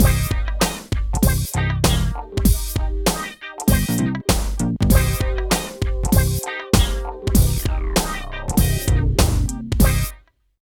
74 LOOP   -R.wav